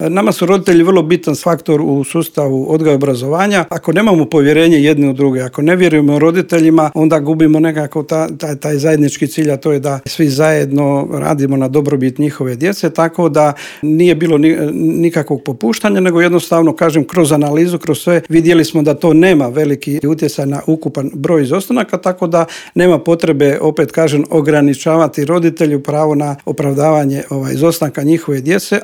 Teme su to o kojima smo u Intervjuu Media servisa razgovarali s ravnateljem Uprave za potporu i unaprjeđenje sustava odgoja i obrazovanja u Ministarstvu obrazovanja Momirom Karinom.